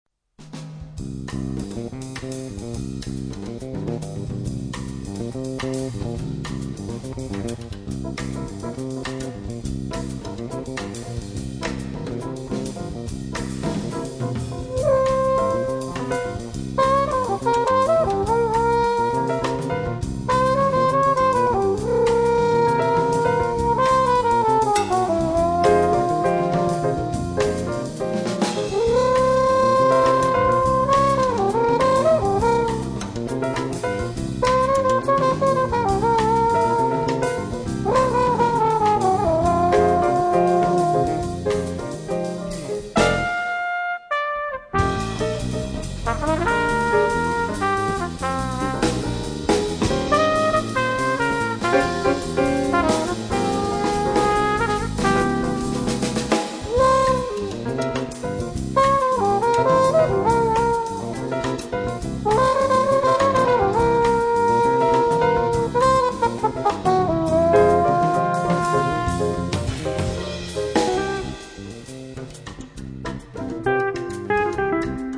TROMBA E FLICORNO
CHITARRA
PIANO
BASSO